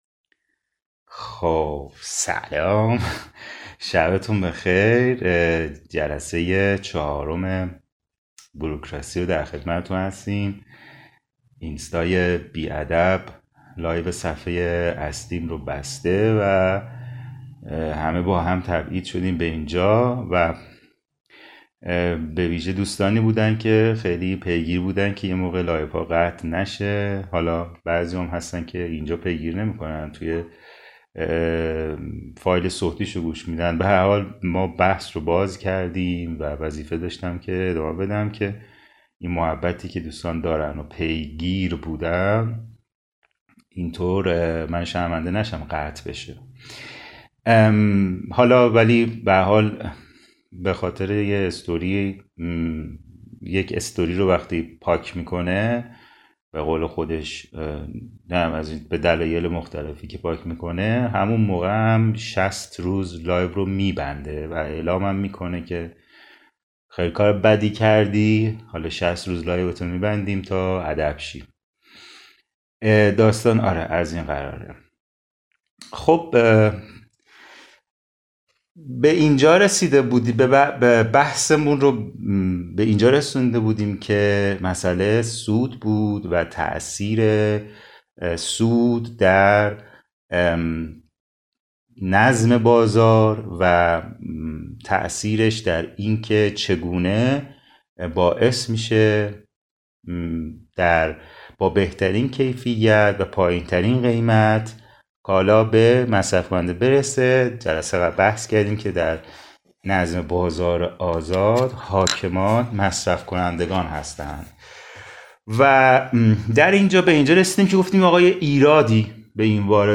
فایل صوتی جلسۀ چهارم خوانش و شرح کتاب «بوروکراسی»، اثر لودویگ فون میزس.